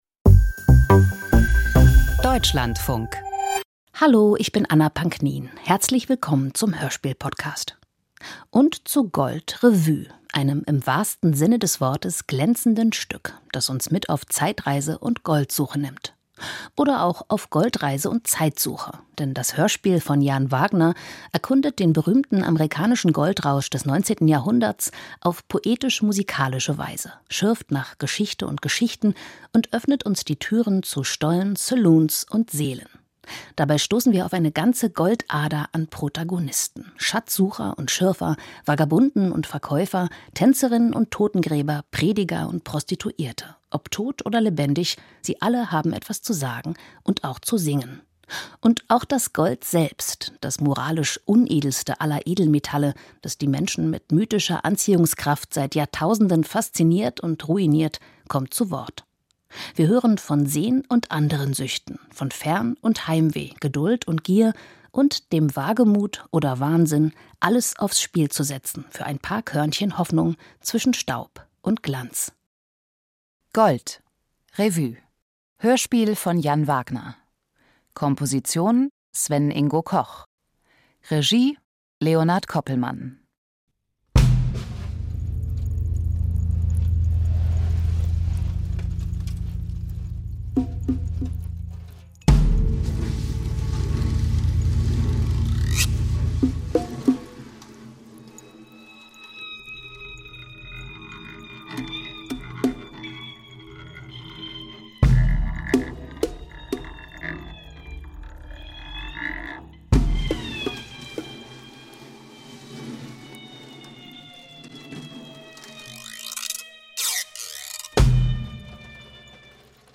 Hörspiel über den Goldrausch - Gold. Revue
Eine Revue zum Rausch, in der die Lebenden und auch die längst vergessenen Toten, verscharrt in der Erde, ein letztes Mal reden dürfen, so wie auch das Gold, das bleibt, während die, die es suchten, vergingen; eine poetische Szenenfolge, ergänzt und gespiegelt von einer Originalmusik des Komponisten Sven-Ingo Koch.